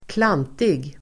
Ladda ner uttalet
klantig adjektiv (vardagligt), clumsy, stupid [informal] Uttal: [²kl'an:tig] Böjningar: klantigt, klantiga Synonymer: klumpig, korkad Definition: oskicklig, dum blundering adjektiv, fumlig , klantig , klumpig